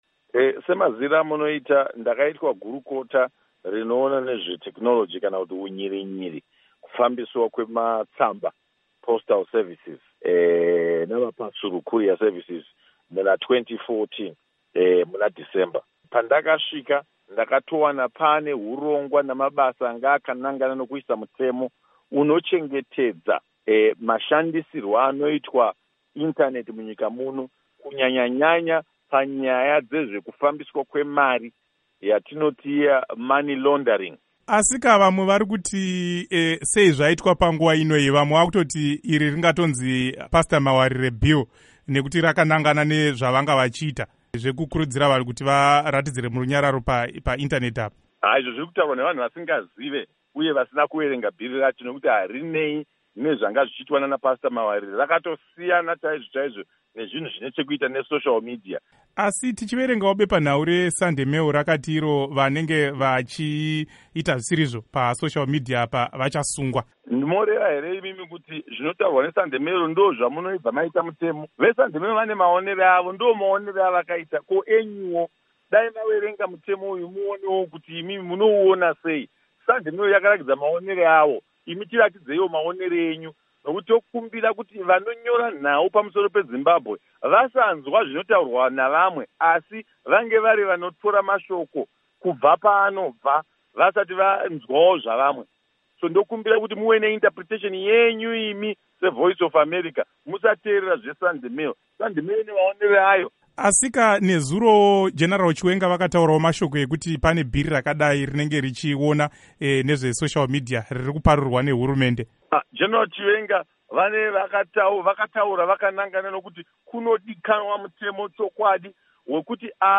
Hurukuro naVaSupa Mandiwanzira